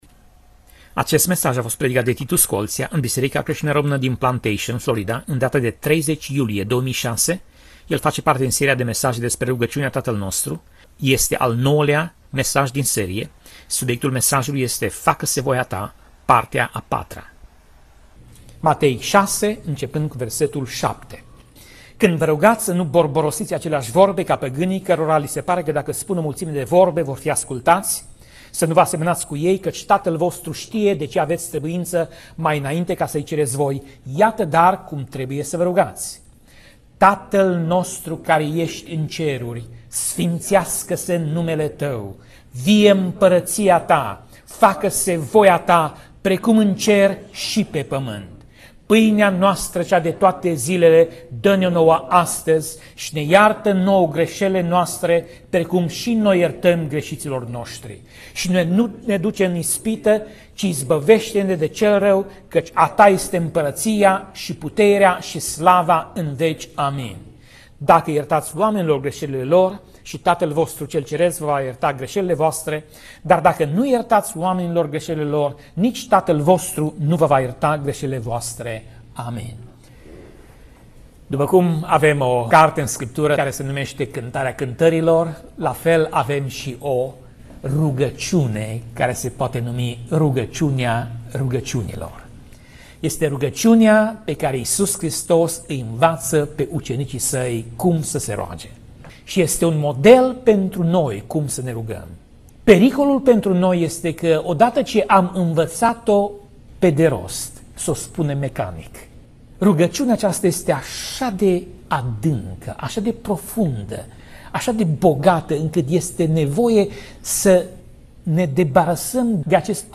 Pasaj Biblie: Matei 6:9 - Matei 6:13 Tip Mesaj: Predica